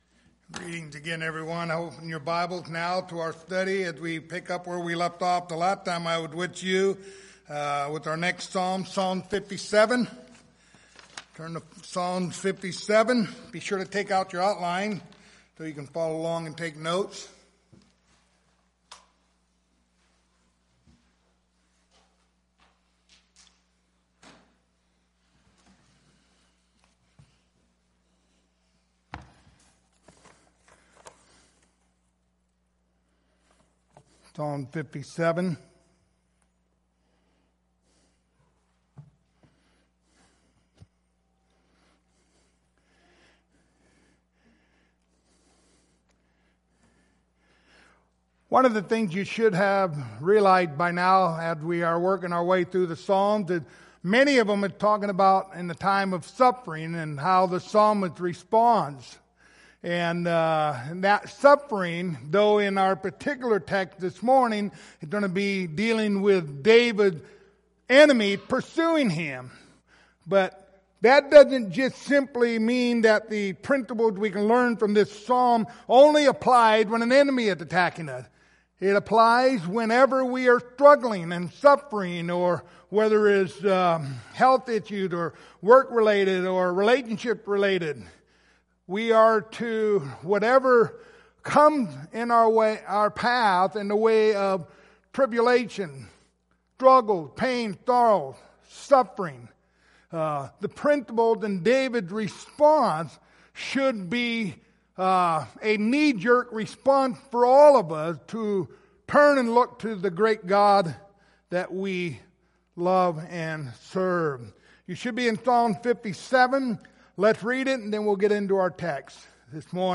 Passage: Psalm 57:1-11 Service Type: Sunday Morning Topics